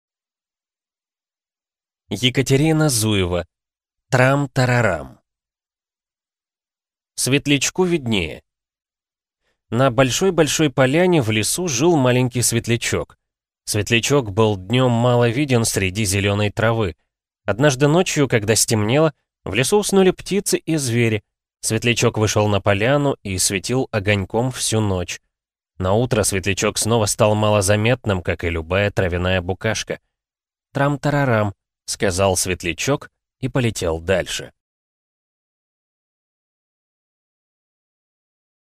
Аудиокнига Трамтарарам | Библиотека аудиокниг